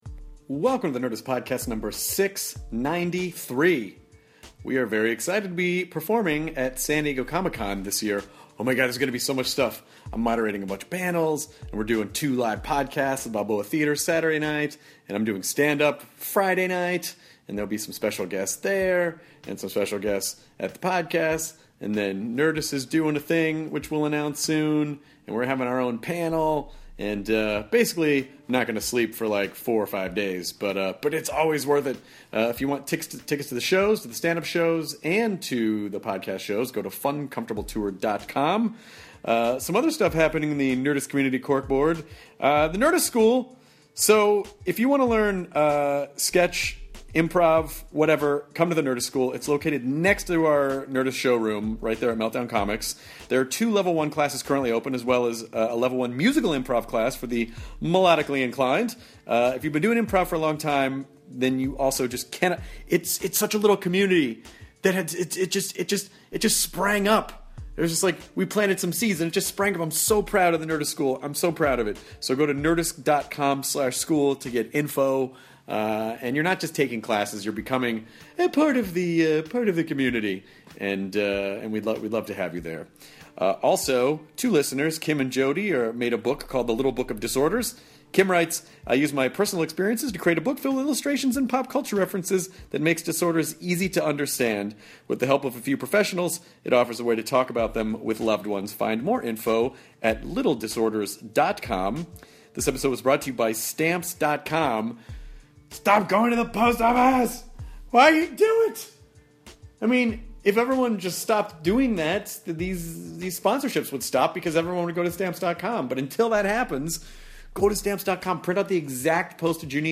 They also talk about different accents and Janet tries out her American accent for a bit!